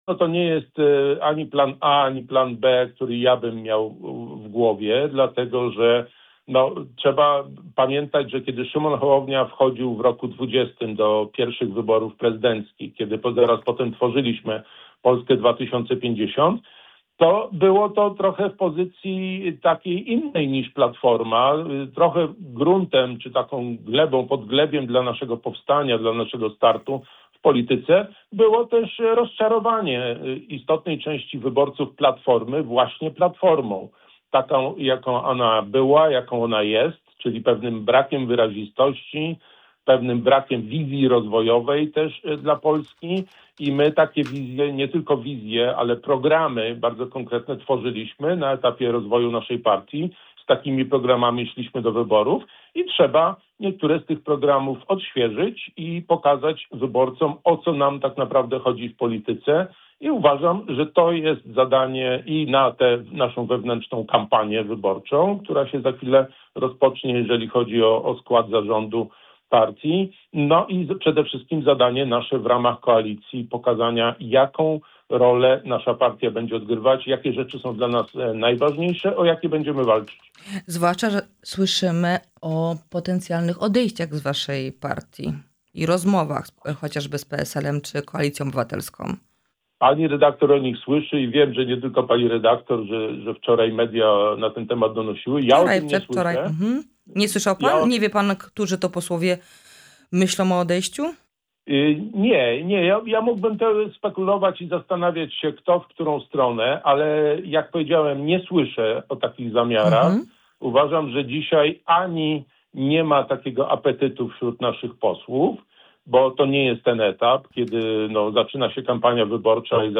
O sytuacji w partii, potencjalnych odejściach, ewentualnej tece wiceministra, a także głosowaniu nad odwołaniem przewodniczącej Komisji Europejskiej rozmawiamy z europosłem Krzysztofem Kobosko.